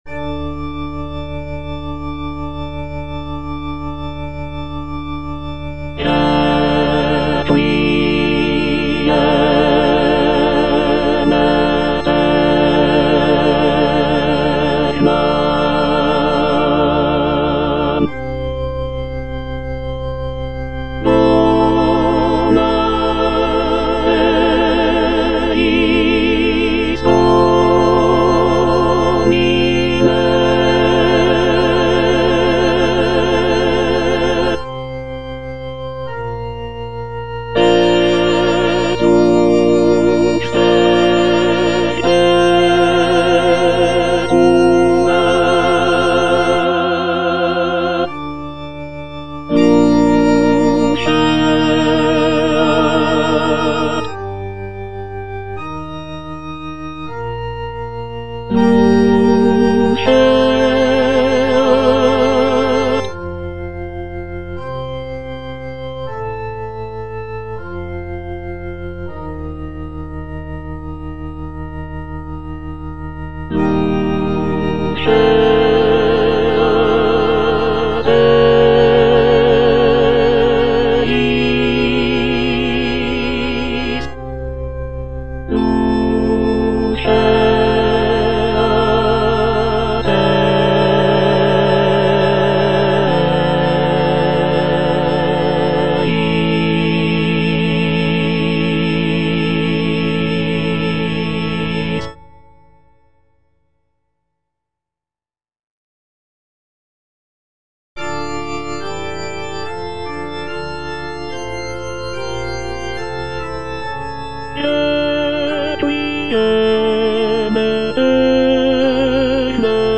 version with a smaller orchestra
(tenor I) (Emphasised voice and other voices) Ads stop